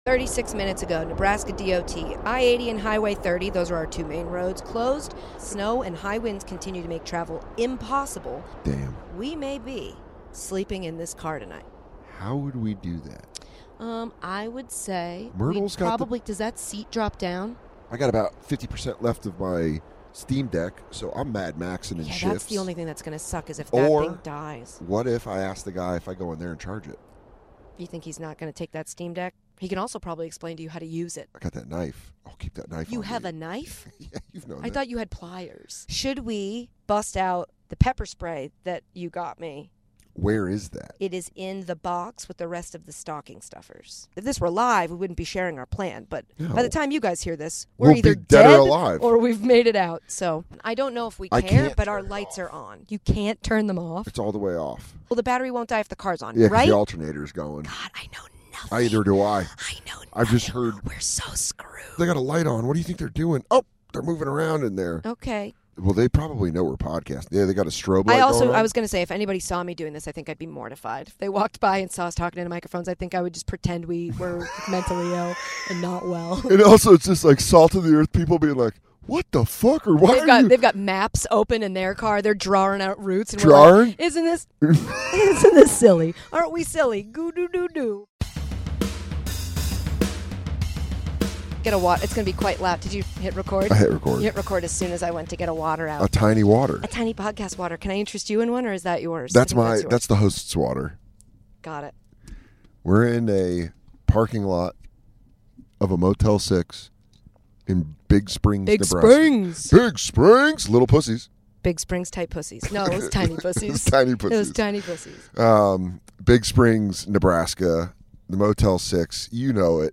While driving through Nebraska, a blizzard shut down the roads. This is the story told from a Motel 6 parking lot.